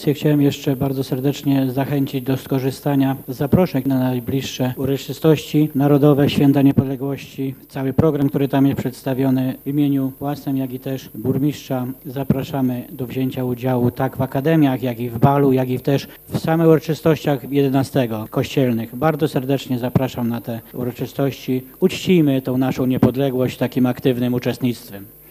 Uroczystości organizują: Urząd Miejski, Starostwo Powiatowe i Szkoła Podstawowa numer 1 w Kolbuszowej. Mówi przewodniczący Rady Miejskiej Krzysztof Wilk: